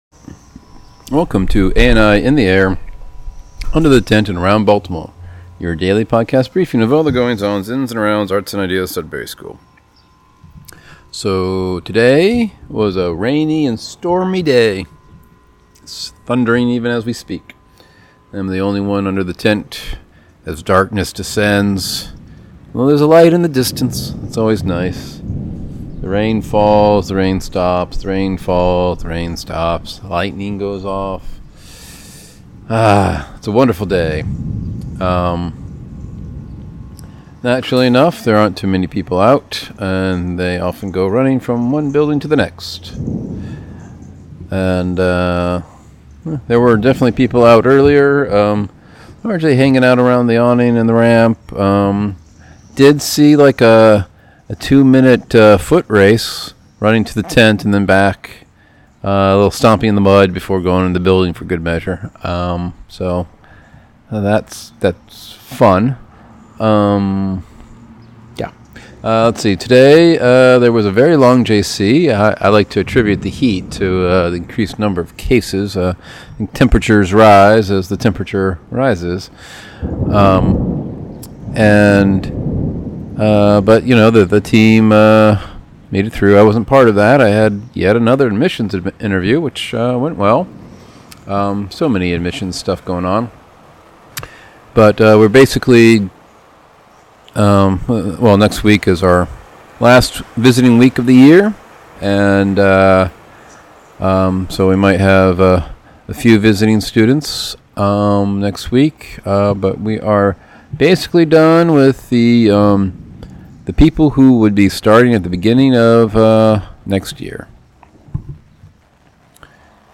Recording during thunderstorm (only one under tent).
Rain blowing into tent.